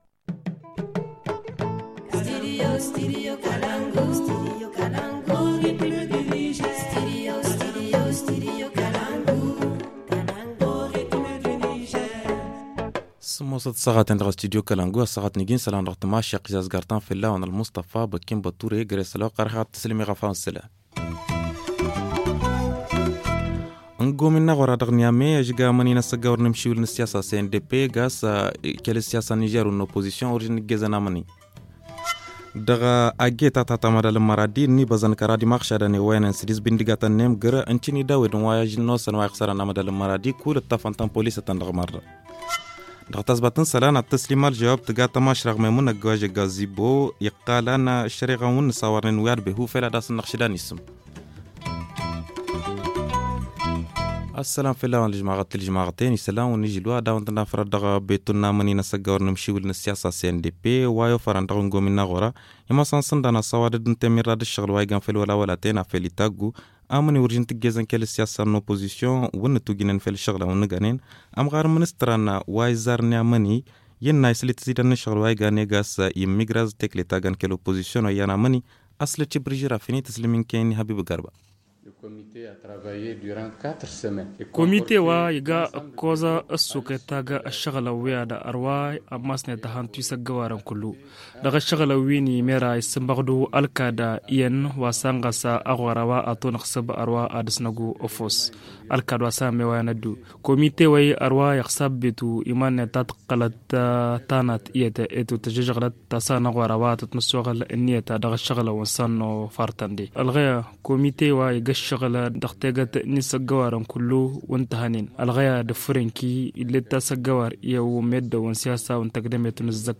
Le journal du 24 janvier - Studio Kalangou - Au rythme du Niger